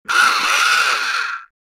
Electric-cheese-grinder.mp3